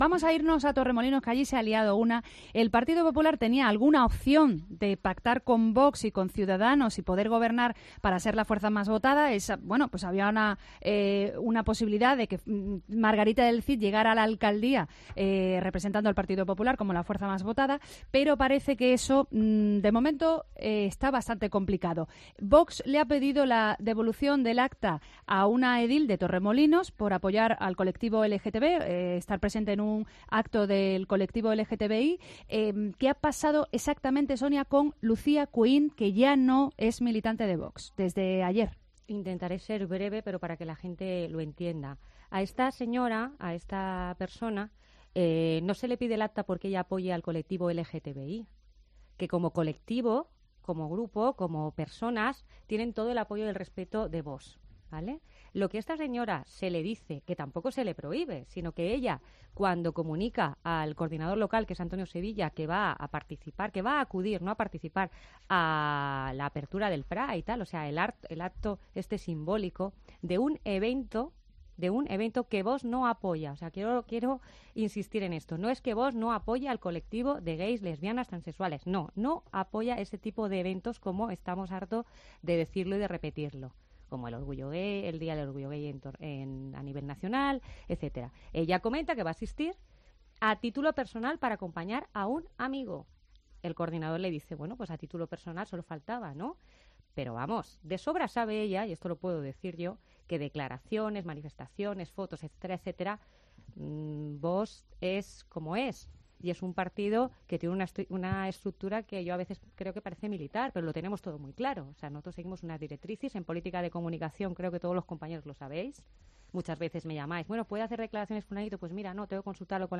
tertulia de mujeres